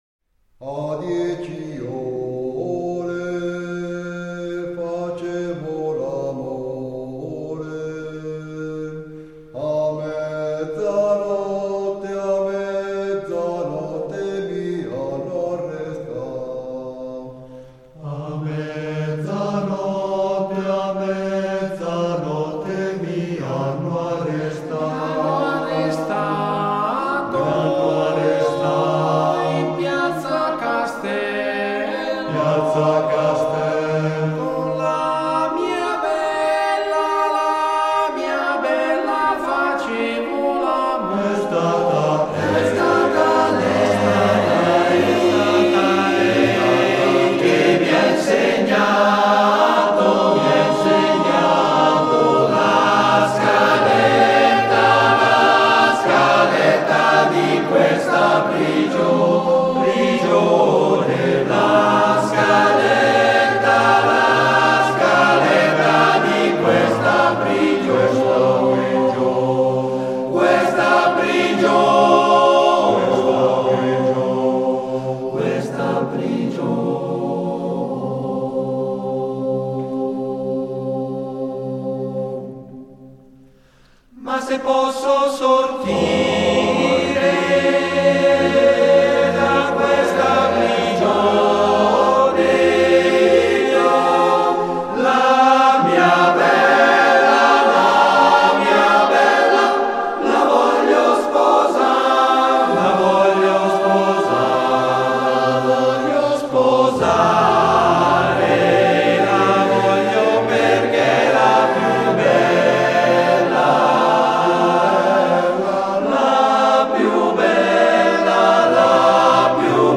Esecutore: Coro SEO CAI Domodossola
Fa parte di: La montagna e la sua gente - 1.: 25 canti di montagna di 25 cori CAI